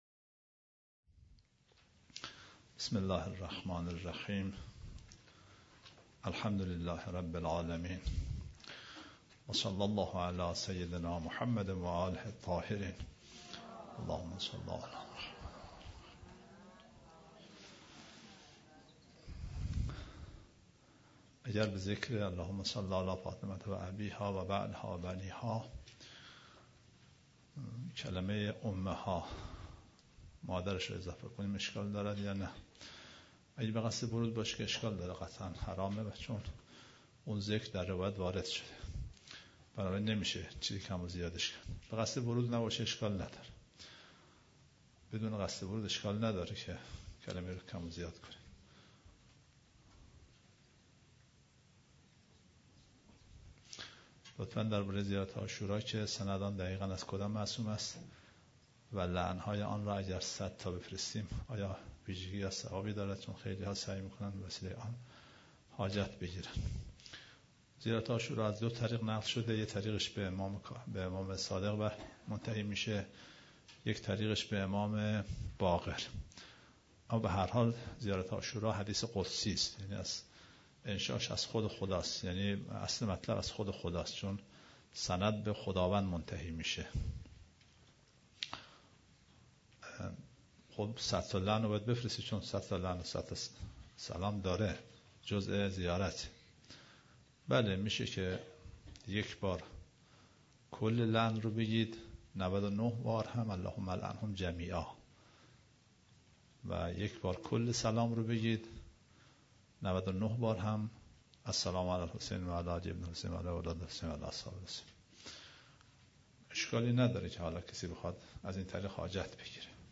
در این جلسه استاد ضمن تدریس در باره سوره الرحمن بیاناتی داشتند که گزیده ای از آن بدین شرح است :